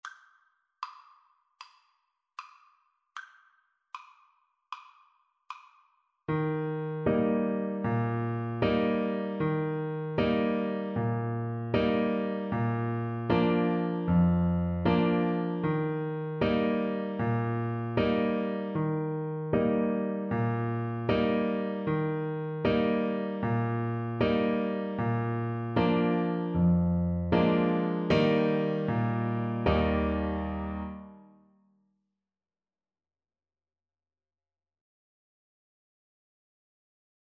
Play (or use space bar on your keyboard) Pause Music Playalong - Piano Accompaniment Playalong Band Accompaniment not yet available transpose reset tempo print settings full screen
Trombone
4/4 (View more 4/4 Music)
Bb3-C5
March
Eb major (Sounding Pitch) (View more Eb major Music for Trombone )